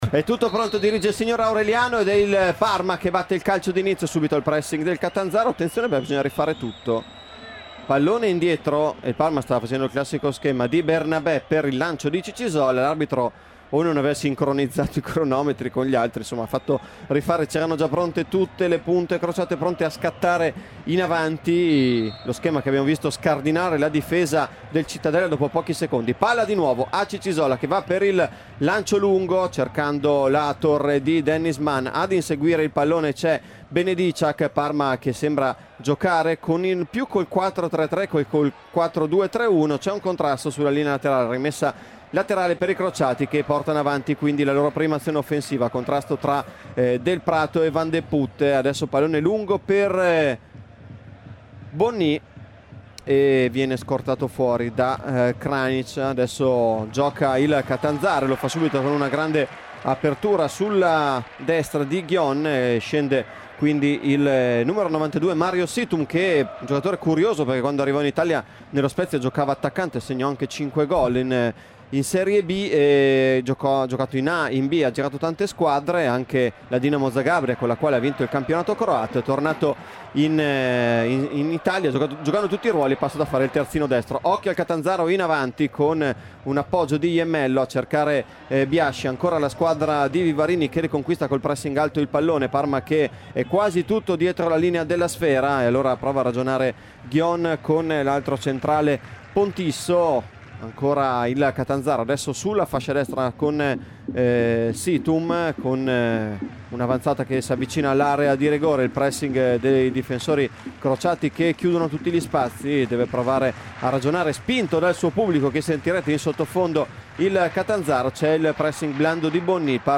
Stadio pieno e ambiente infuocato: cento circa i tifosi crociati a seguito dei ragazzi di Pecchia.